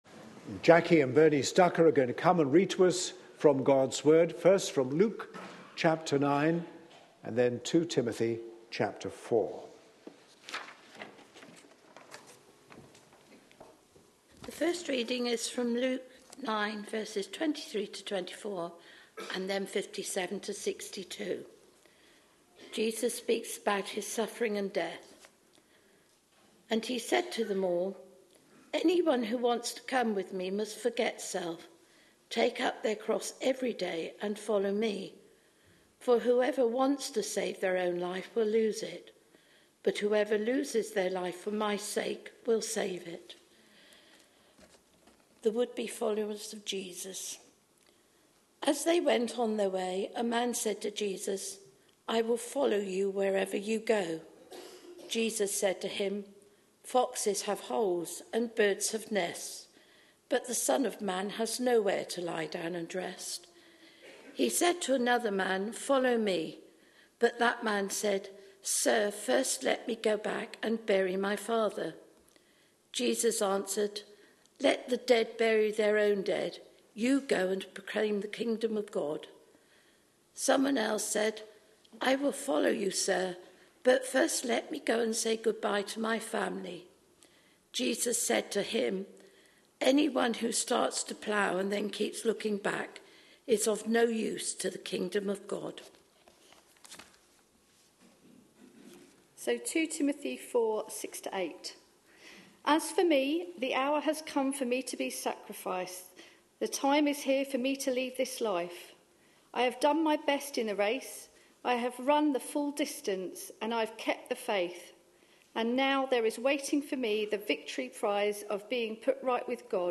A sermon preached on 26th January, 2014, as part of our Jesus -- His Challenge To Us Today. series.